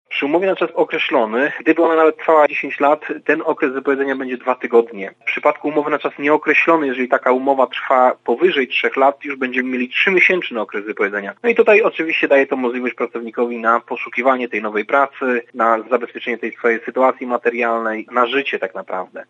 tłumaczy radca prawny